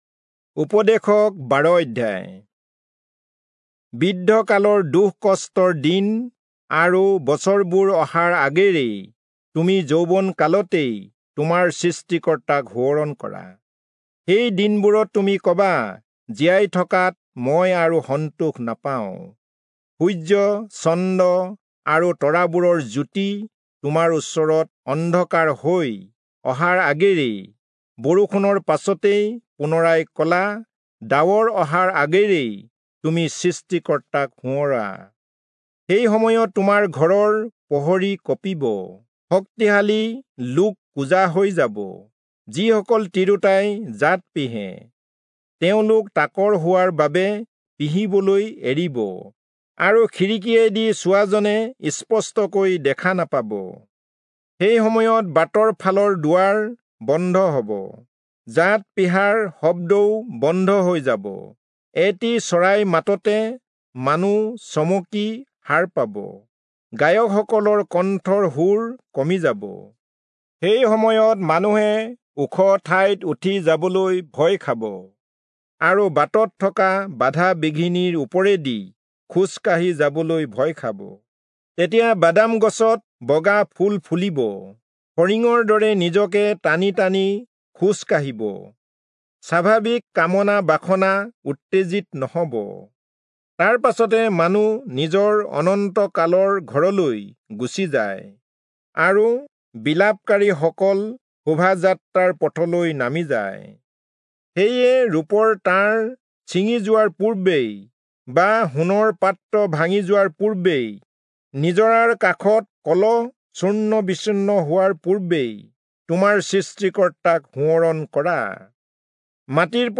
Assamese Audio Bible - Ecclesiastes 12 in Irvhi bible version